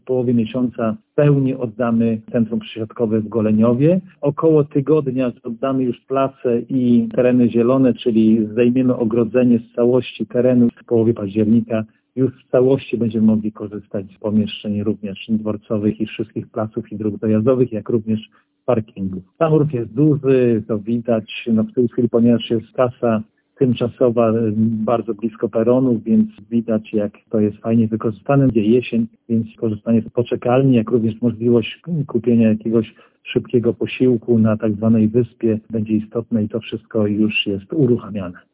– Mówi Tomasz Banach, zastępca burmistrza gminy Goleniów.